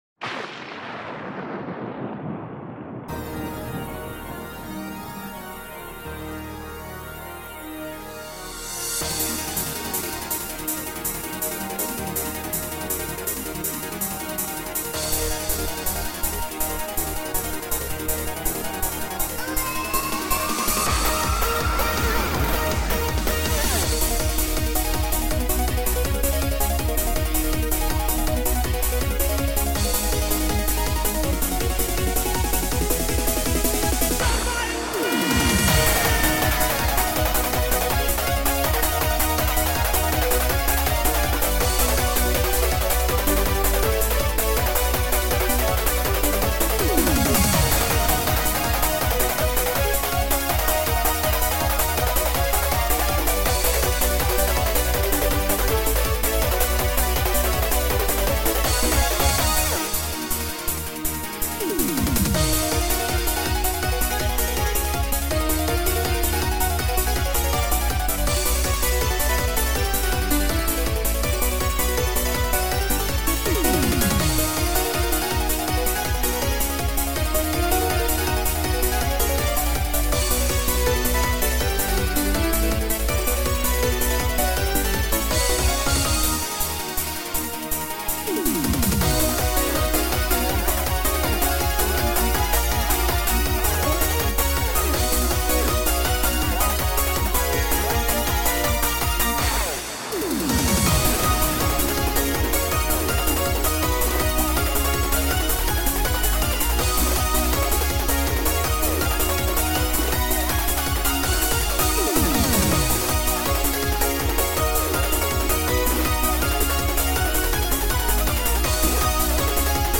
genre:eurobeat